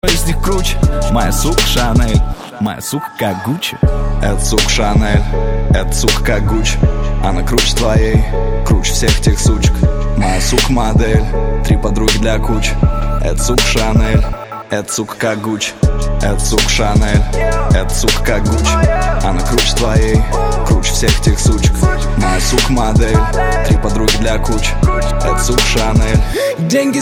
• Качество: 128, Stereo
Хип-хоп
русский рэп
качающие
грубые
Нарезка качающего трека